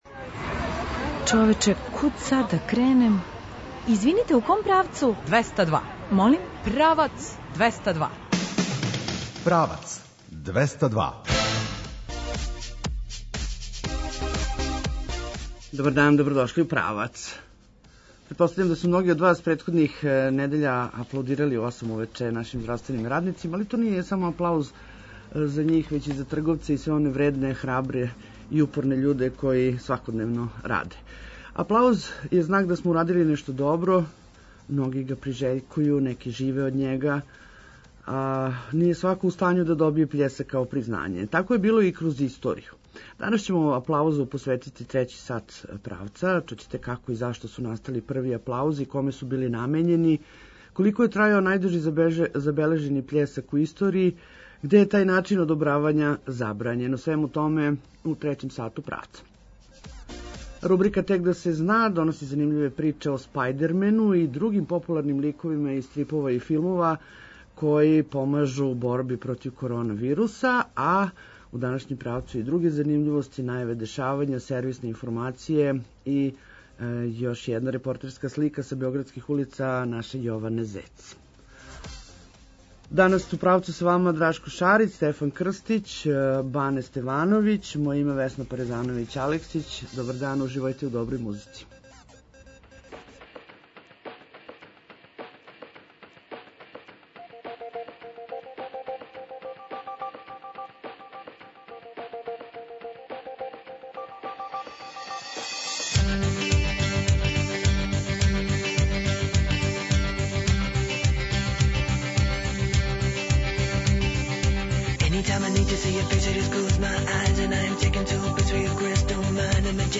Рубрика „Тек да се зна” доноси занимљиве приче о Спајдермену и другим популарним ликовима који су послужили у борби против короне. Ту су и друге занимљивости, најаве дешавања, сервисне информације и репортерска слика са београдских улица.